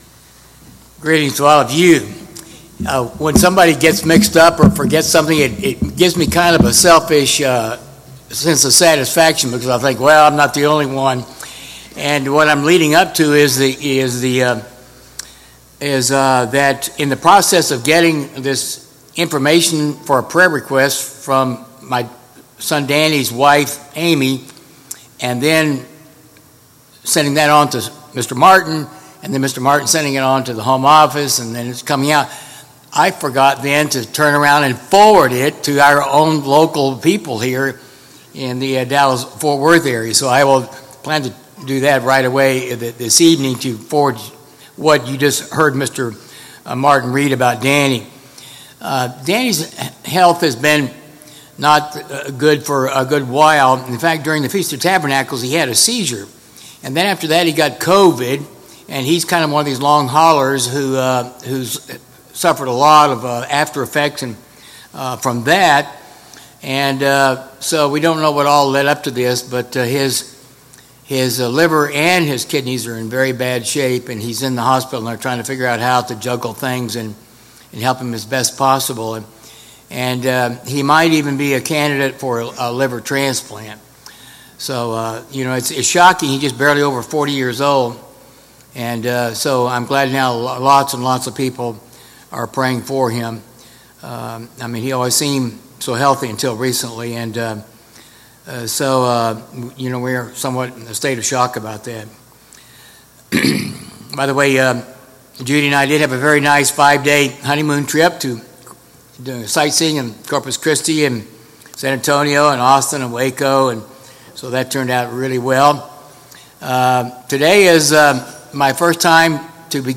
The more one understands grace and experiences it, the more amazing it is. It’s important to focus on God’s grace before Passover (a celebration of God’s grace). In this sermon, grace is illustrated by summarizing the lives of John Newton & Wm Wilberforce (two men who were deeply grateful for God’s grace.)